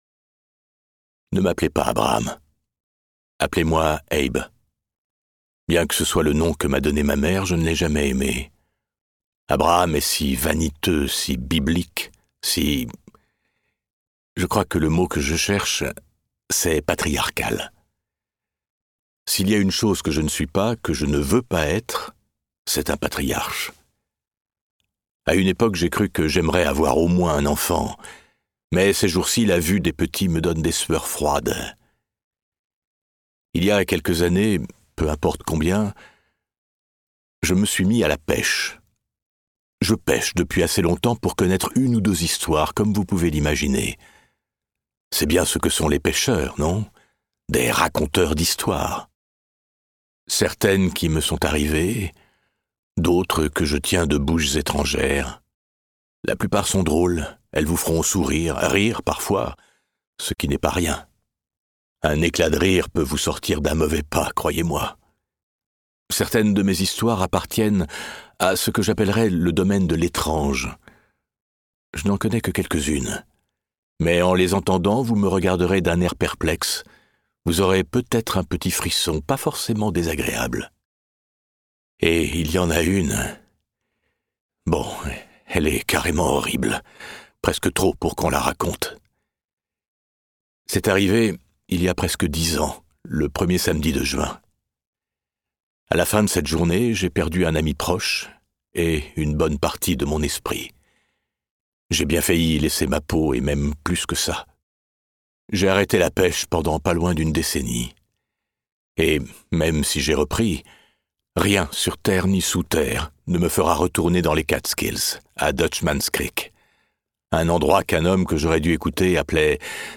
la voix sombre et profonde